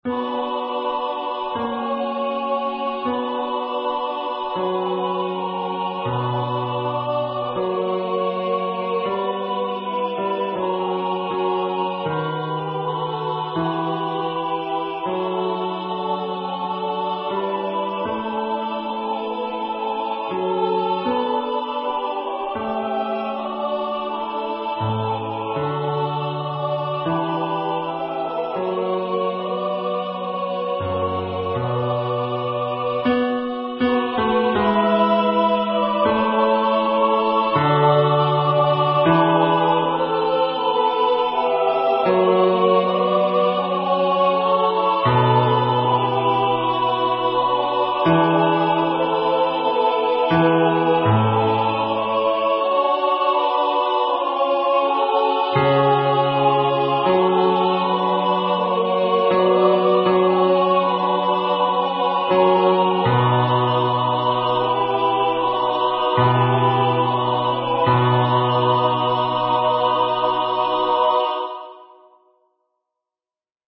with Accompaniment
MP3 Practice Files: Soprano:   Alto:   Tenor:   Bass:
Number of voices: 4vv   Voicing: SATB
Genre: SacredMotetOffice hymn
Instruments: A cappella